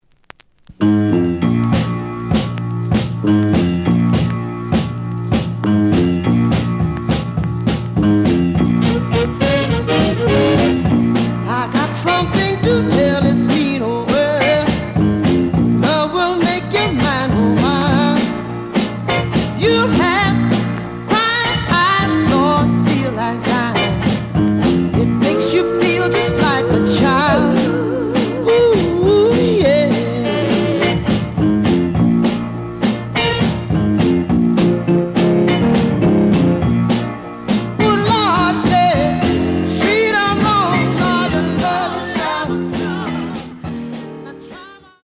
The audio sample is recorded from the actual item.
●Genre: Soul, 60's Soul
Some click noise on beginning of B side due to scratches.